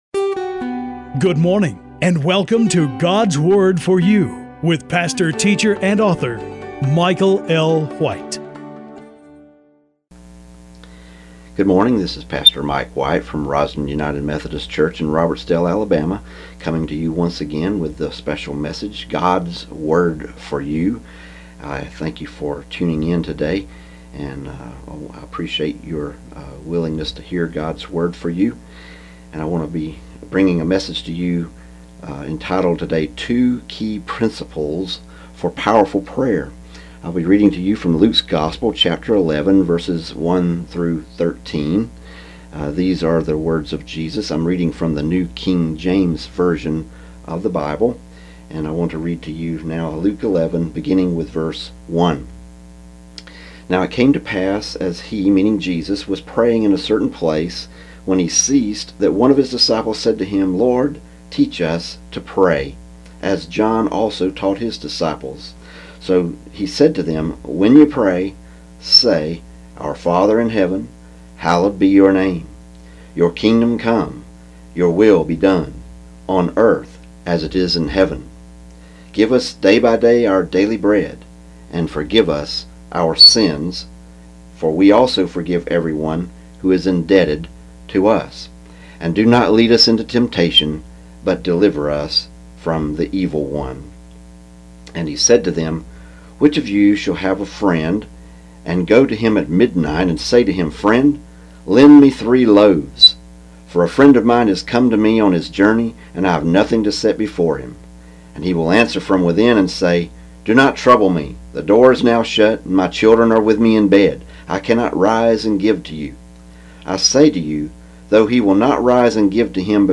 God's Word for You: Recorded Inspirational Sermons